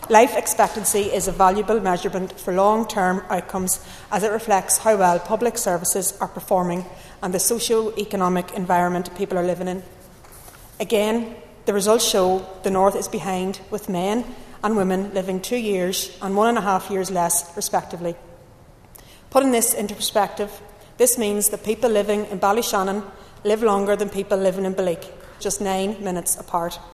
Speaking on a Sinn Fein motion noting the ESRI’s report Assessing Economic Trends on the Island of Ireland, Fermanagh and South Tyrone MLA Jemma Dolan said the report shows there’s a distinct difference between her home in Belleek, and just across the border in Donegal………